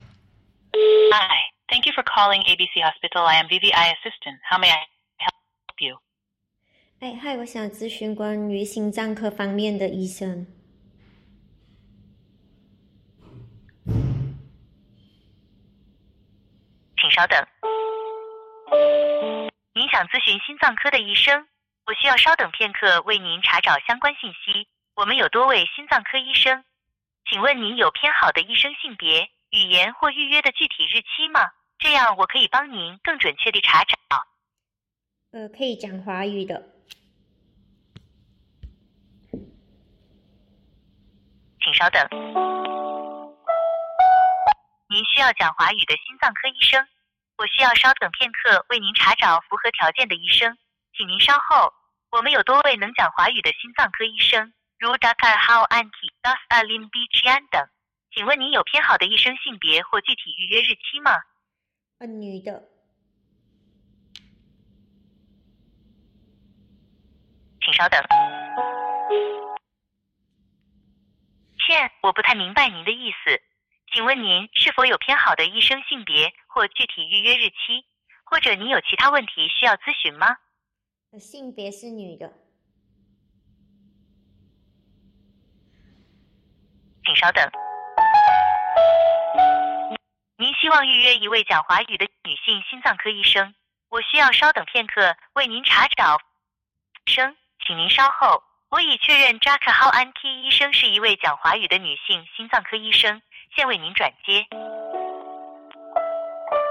It is human-like intelligence, perpetually ready to listen, understand, and respond.
Click the play button below to check out Wevetel AI Chinese Version